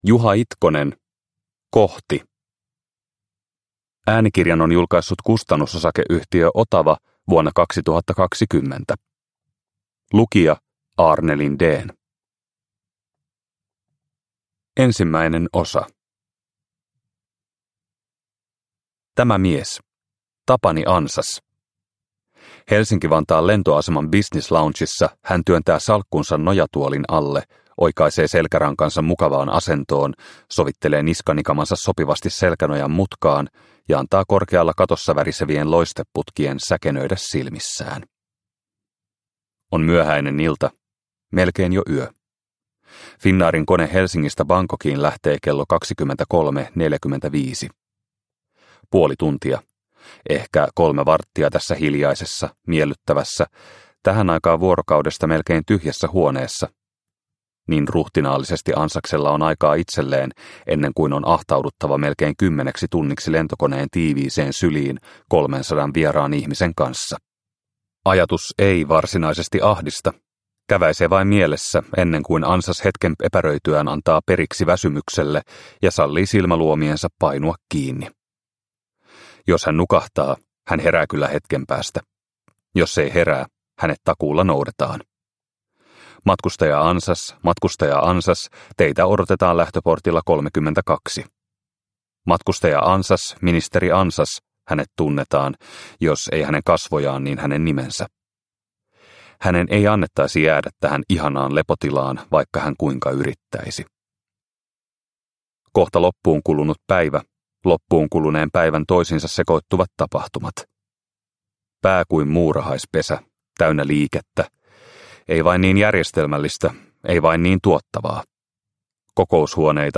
Kohti – Ljudbok – Laddas ner